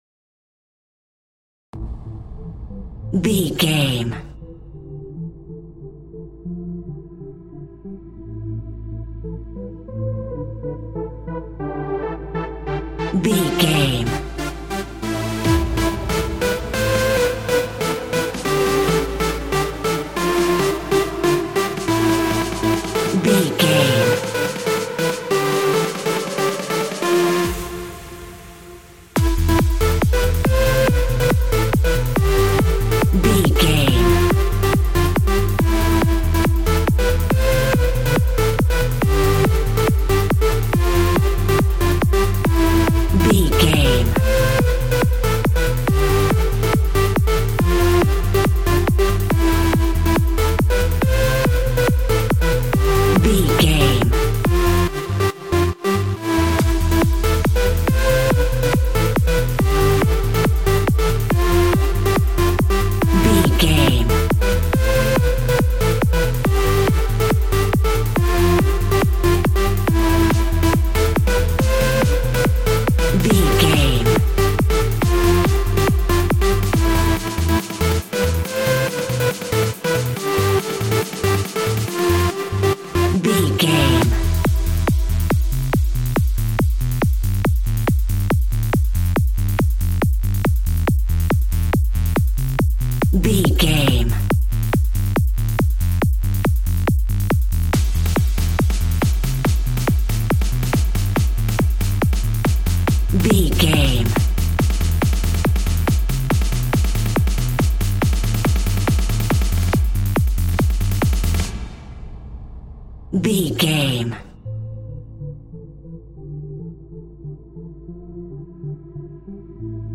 Aeolian/Minor
groovy
uplifting
driving
energetic
repetitive
drum machine
synthesiser
acid house
electronic
uptempo
synth leads
synth bass